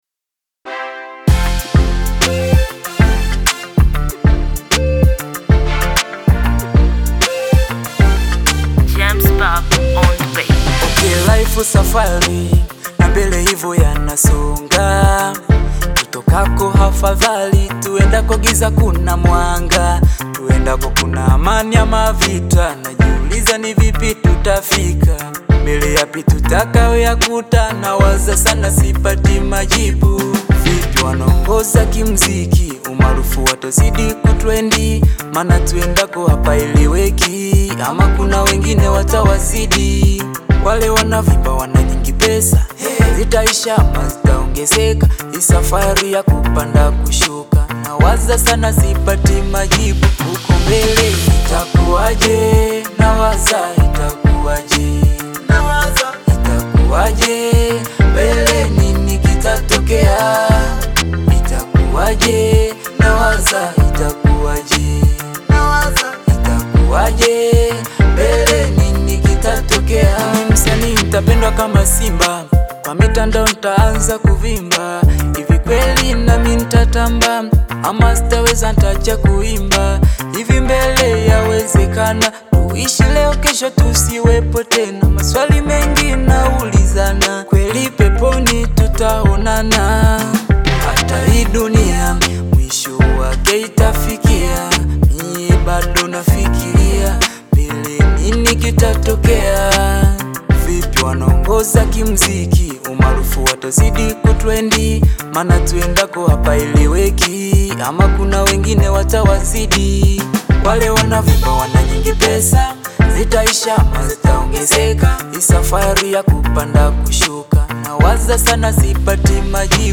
Audio Bongo flava Latest